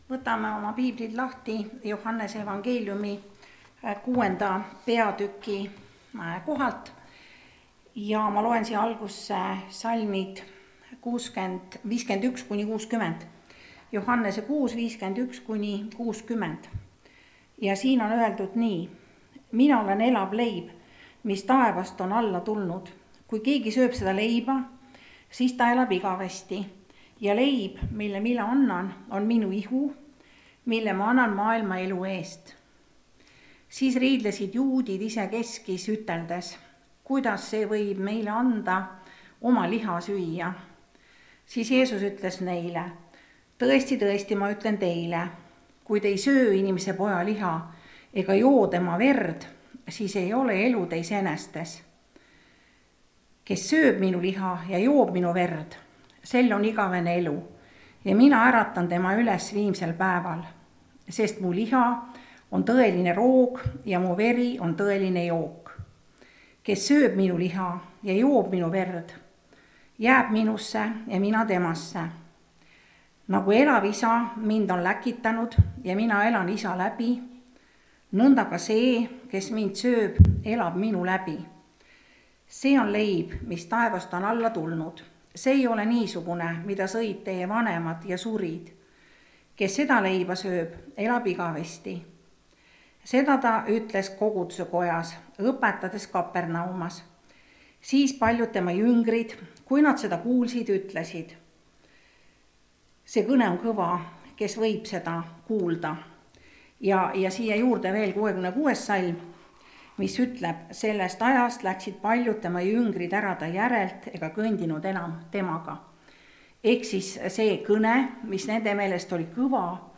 kategooria Audio / Jutlused